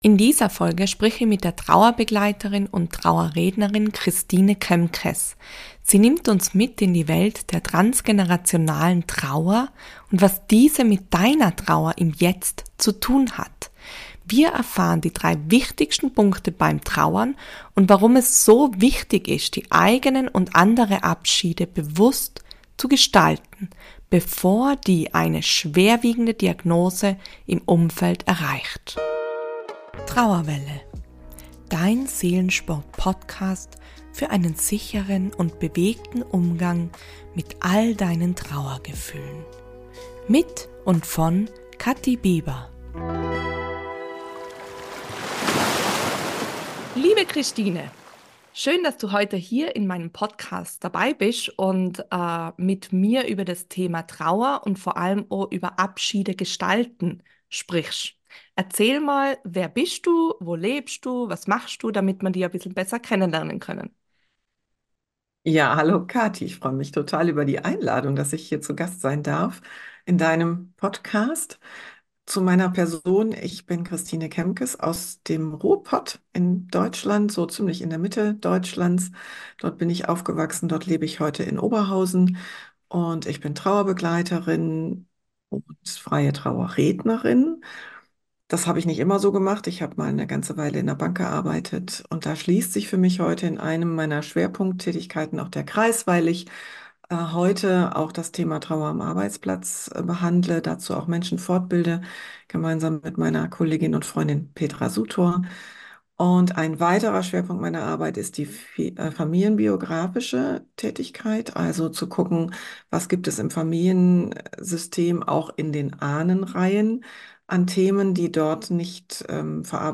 #44 Transgenerationale Trauer und Abschied gestalten - Interview